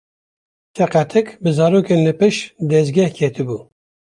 Pronunciado como (IPA)
/pɪʃt/